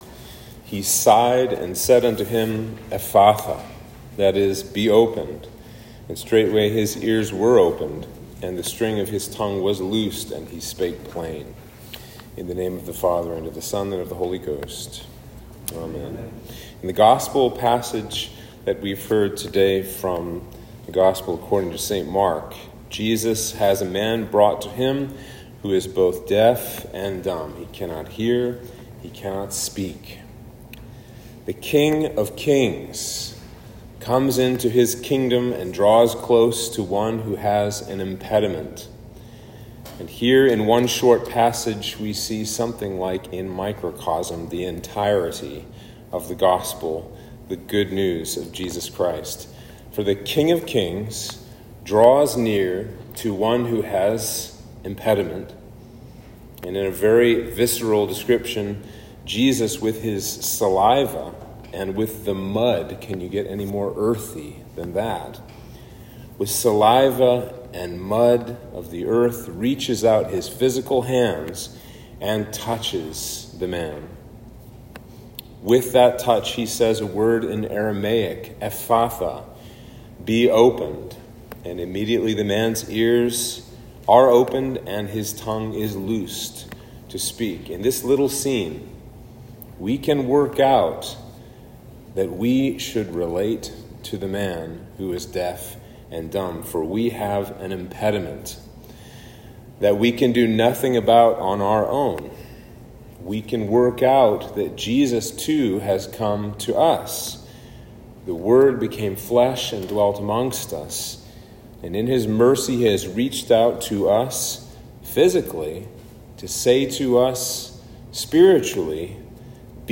Sermon for Trinity 12